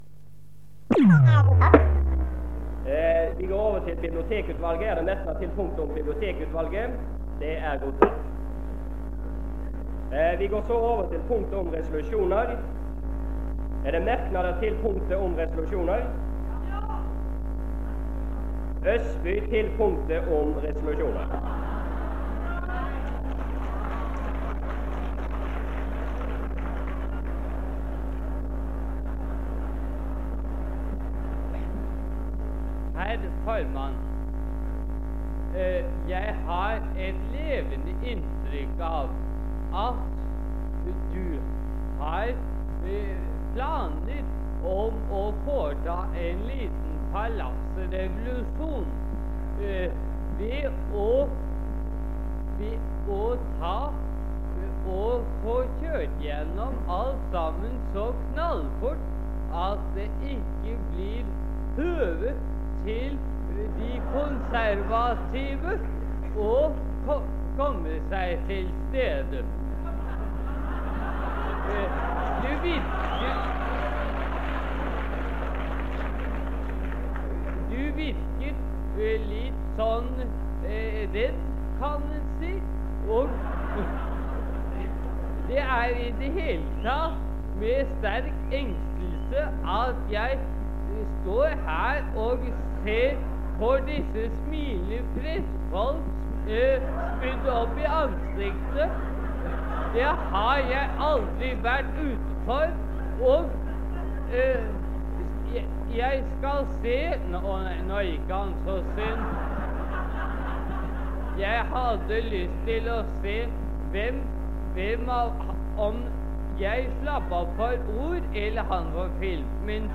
Det Norske Studentersamfund, Generalforsamling, 06.05.1967